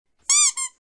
جلوه های صوتی
دانلود صدای اردک 3 از ساعد نیوز با لینک مستقیم و کیفیت بالا
برچسب: دانلود آهنگ های افکت صوتی اشیاء دانلود آلبوم صدای سوت اردک اسباب بازی از افکت صوتی اشیاء